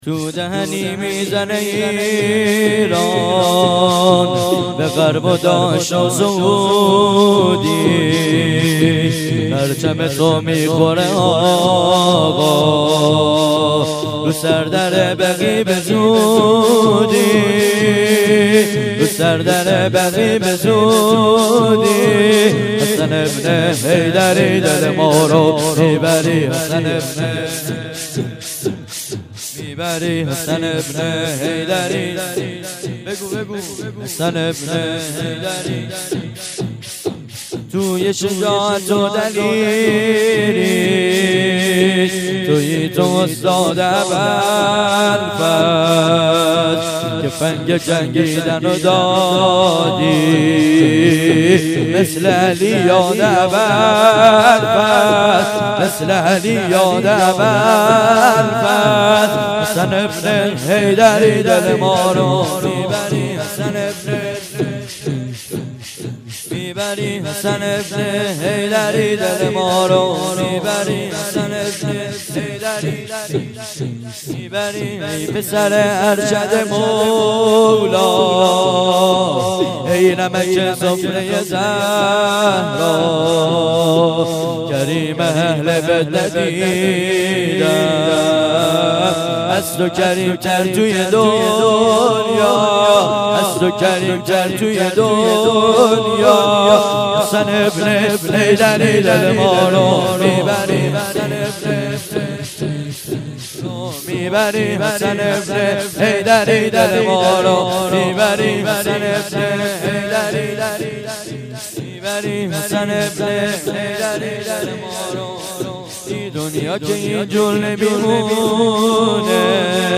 مراسم تخریب بقیع
(شور)